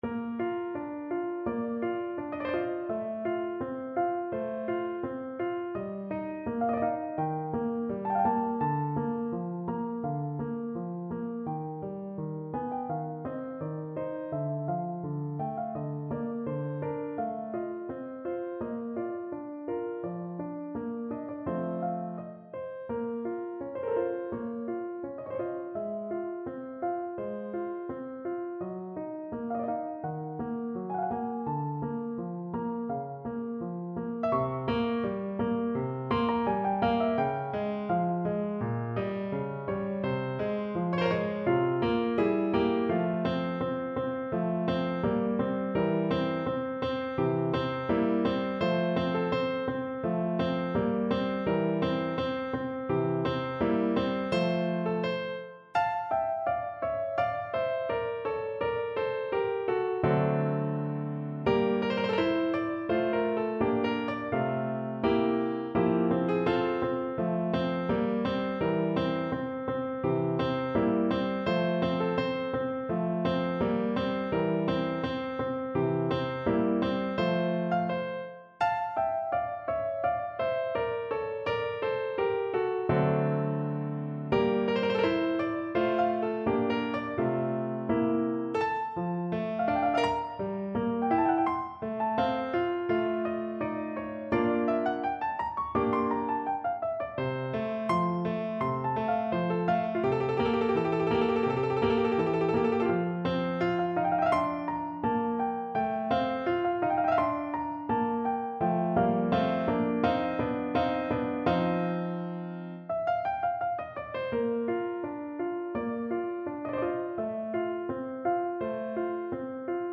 No parts available for this pieces as it is for solo piano.
Adagio =c.84
4/4 (View more 4/4 Music)
Piano  (View more Intermediate Piano Music)
Classical (View more Classical Piano Music)